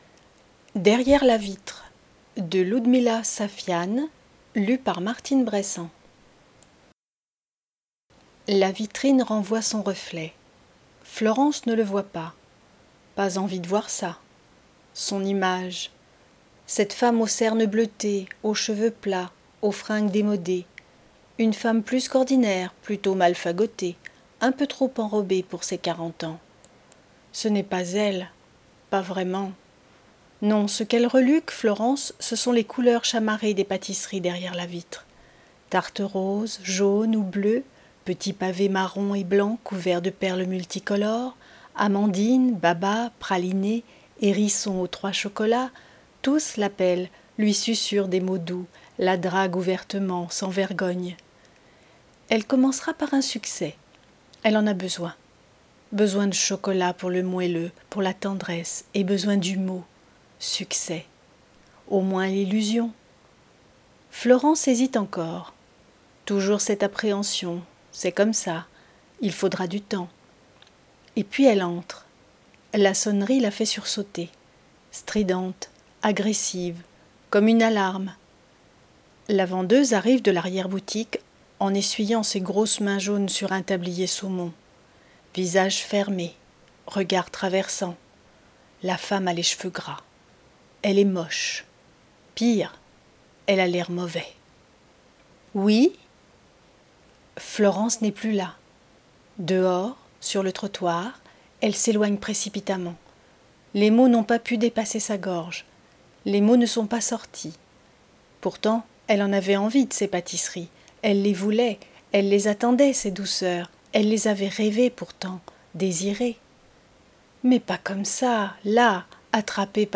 Lecture � haute voix - Derri�re la vitre
NOUVELLE